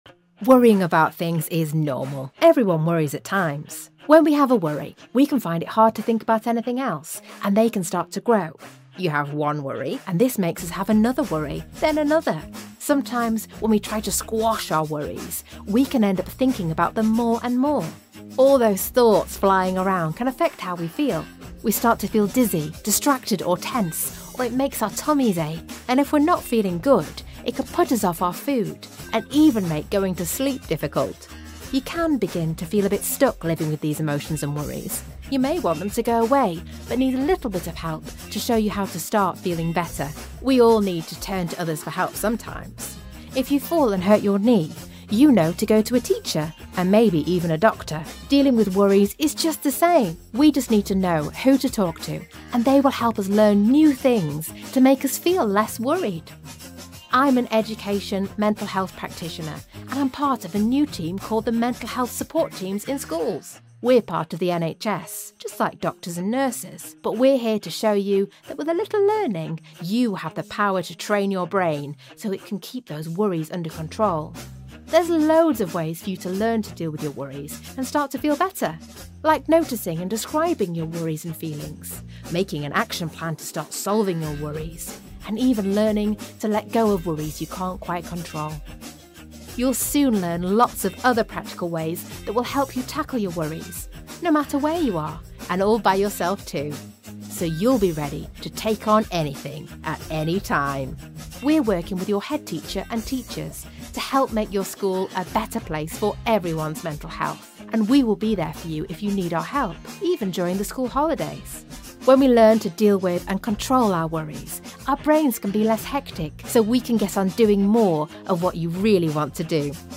Natural, Versátil, Amable
Explicador
She has a broadcast quality home studio and is a popular choice amongst clients.